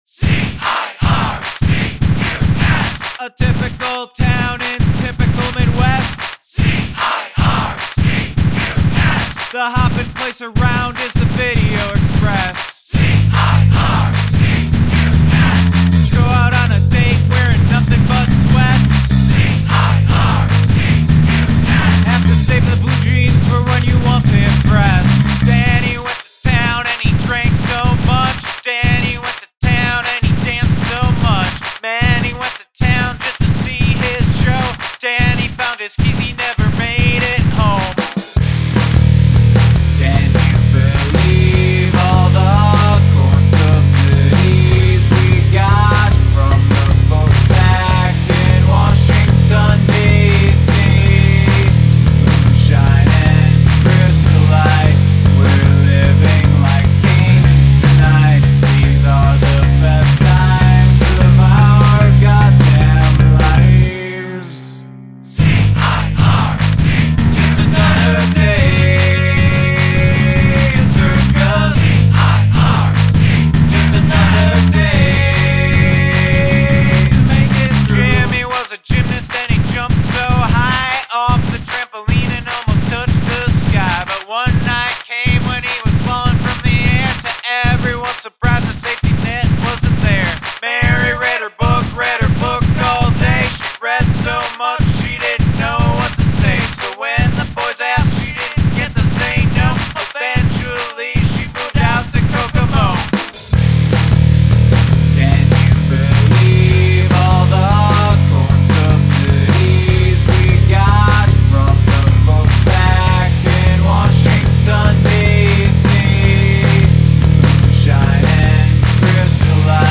Use something from your garbage bin as an instrument
Lyrics are right up front and not particularly interesting to me. The energy is good. Very pop feel. Vocals fit the style well.